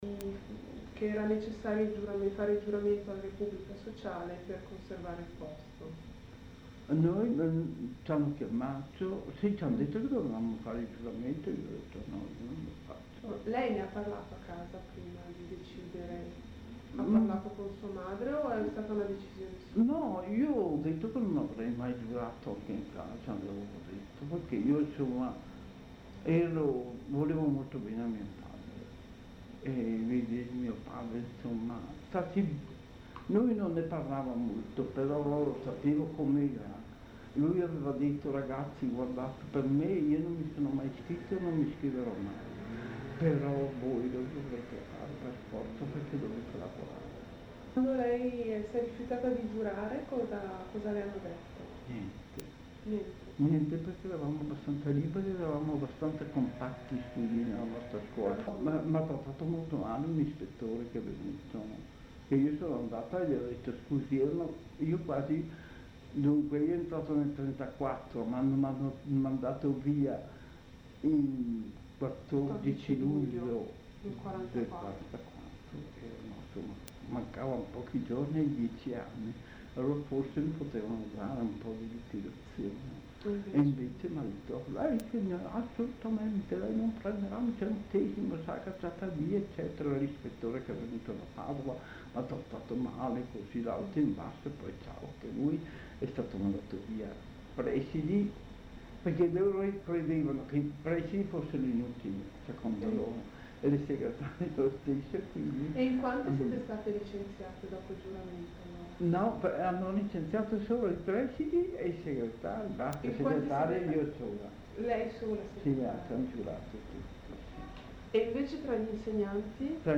Estratto dall’intervista